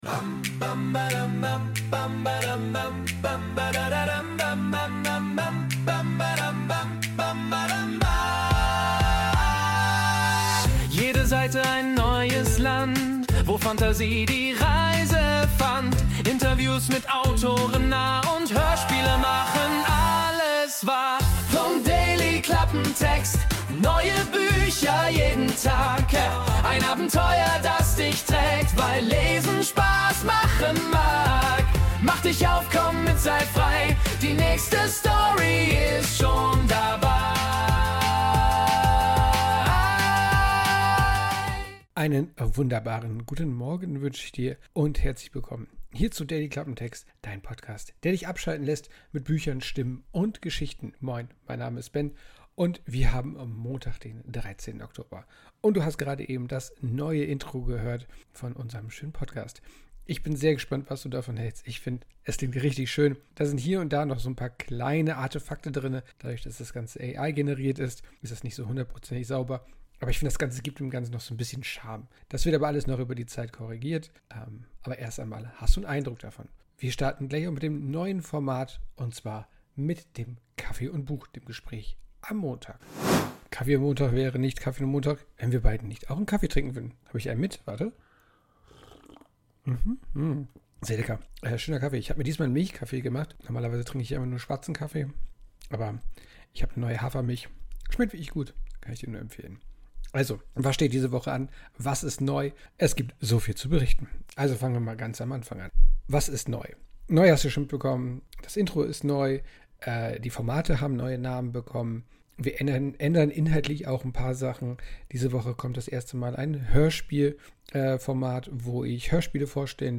Intro: Wurde mit der Ai Music AI generiert.